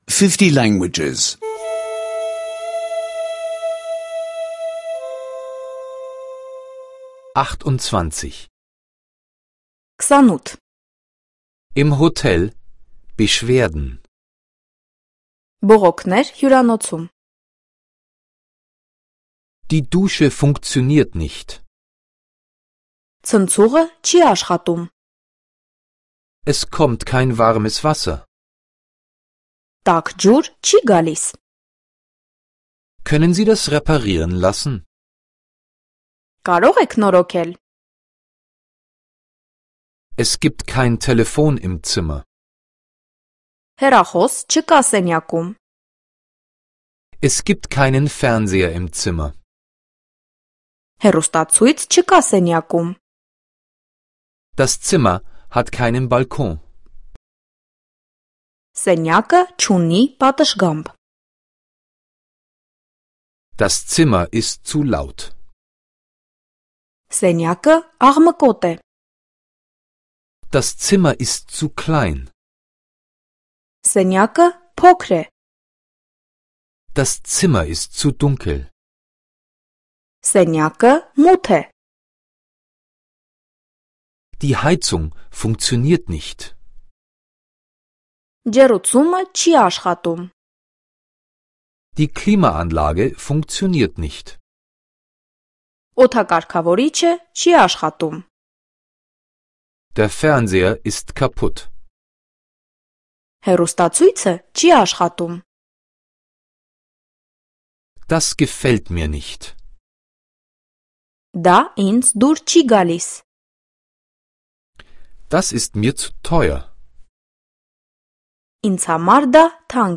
Armenische Audio-Lektionen, die Sie kostenlos online anhören können.